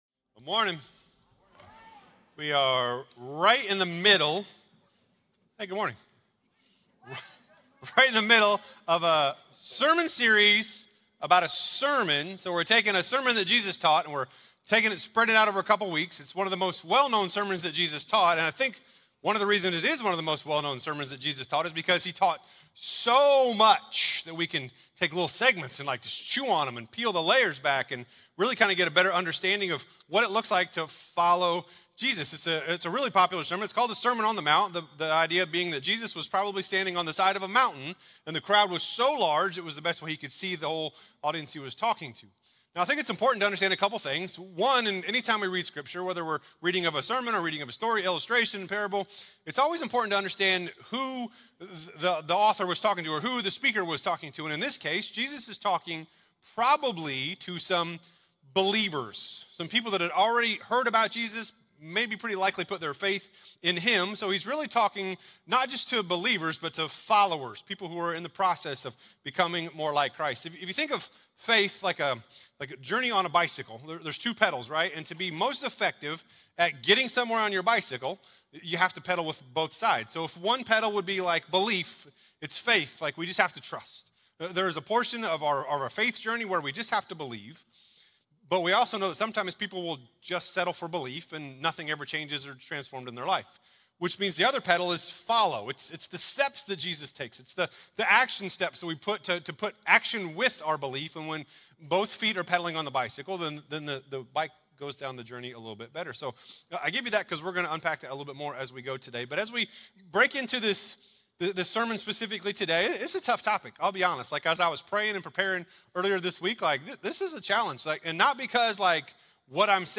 Crossroads Community Church - Audio Sermons 2023-06-25 - A Divided Heart Play Episode Pause Episode Mute/Unmute Episode Rewind 10 Seconds 1x Fast Forward 10 seconds 00:00 / 44:09 Subscribe Share RSS Feed Share Link Embed